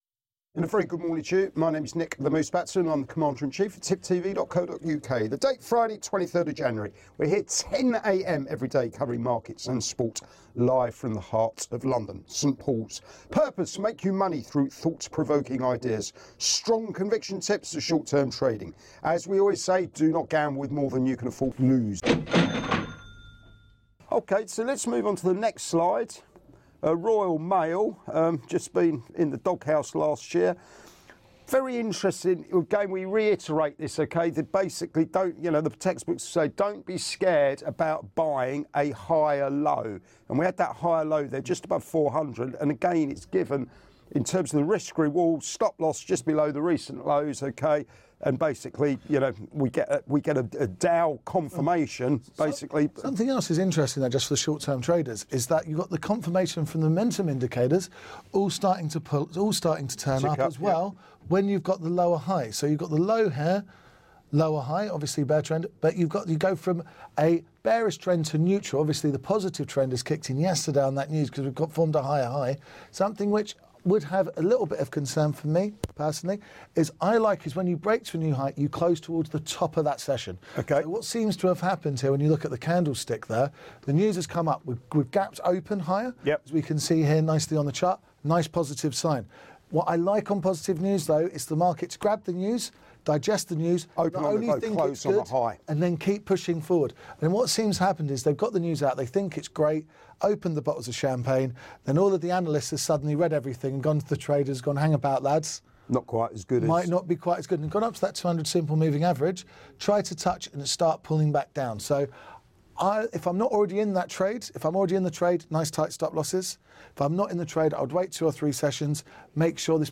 Live Market Round-Up & Soapbox thoughts